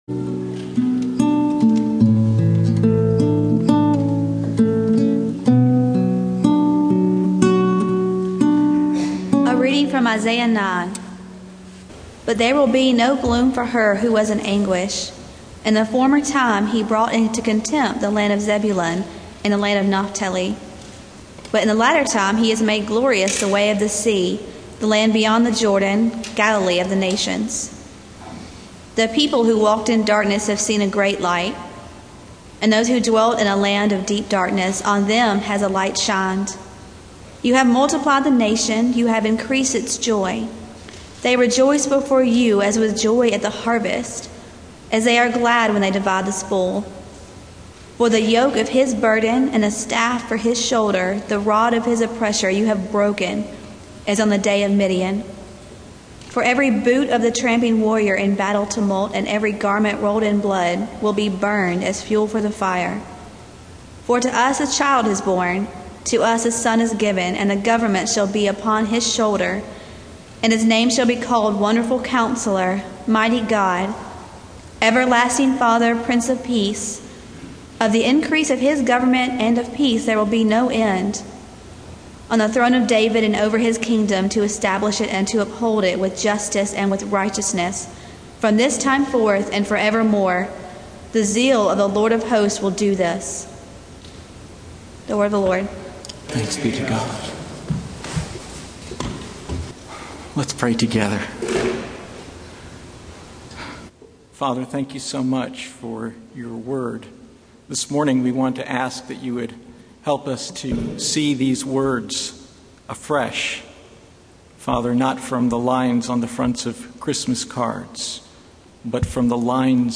Passage: Isaiah 9:1-7 Service Type: Sunday Morning